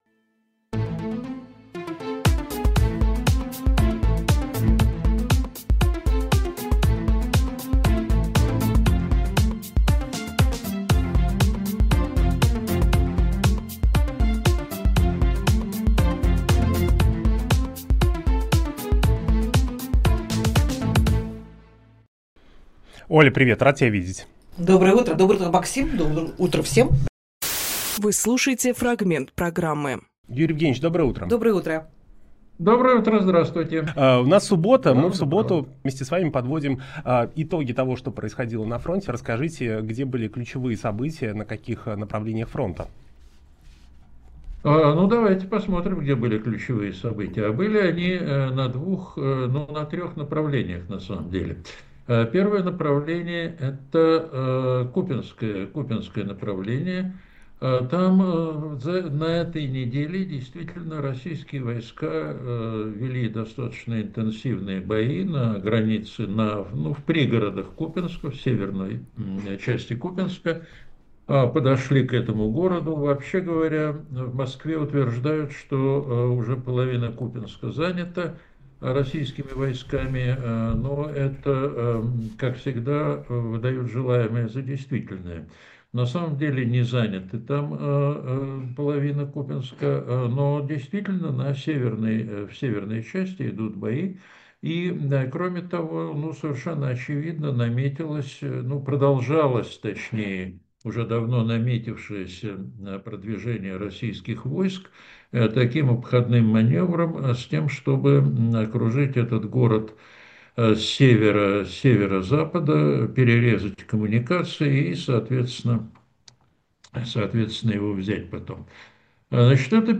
Фрагмент эфира от 06.09.25
военно-политический эксперт